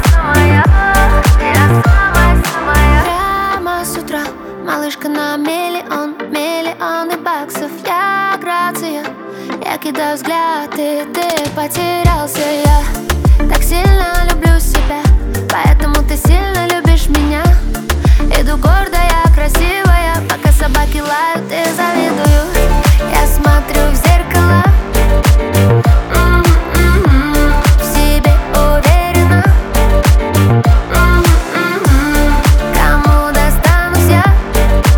Жанр: Поп музыка / Танцевальные / Русский поп / Русские
Pop, Dance